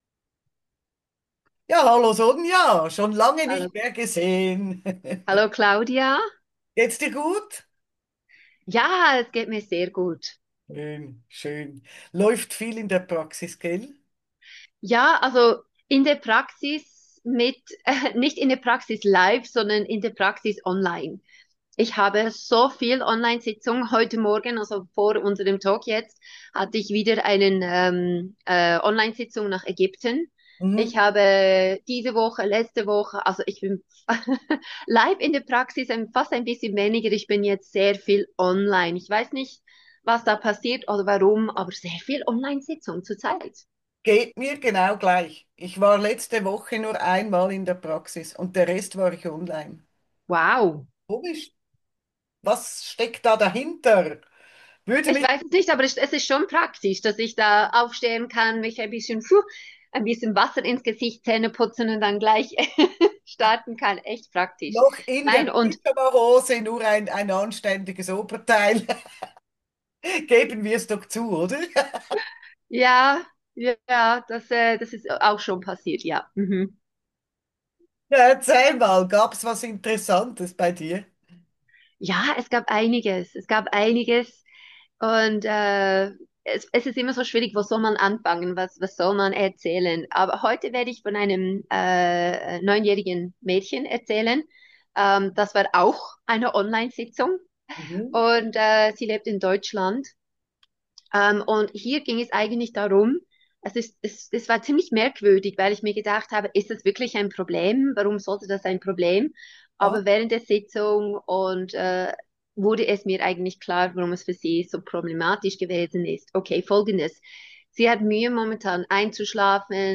Der spontane mindTV Wochentalk